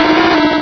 pokeemerald / sound / direct_sound_samples / cries / quagsire.aif
quagsire.aif